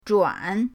zhuan3.mp3